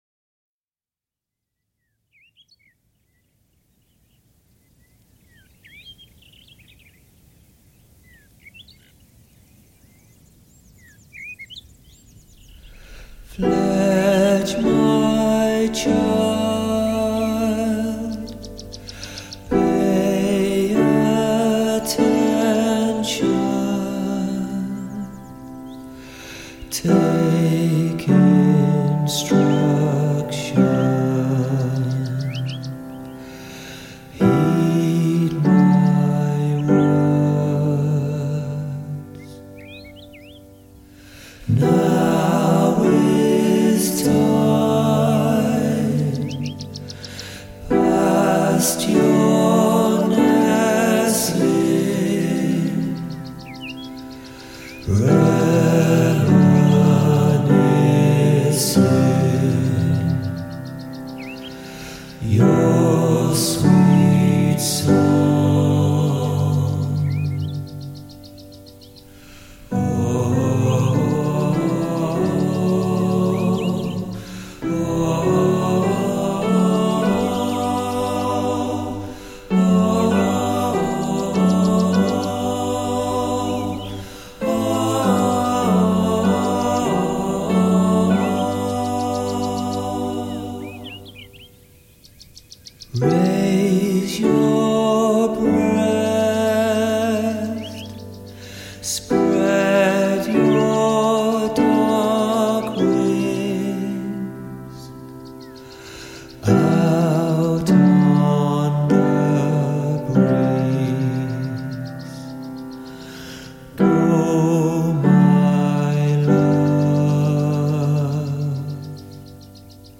Birds in Pat Busch Reserve, South Africa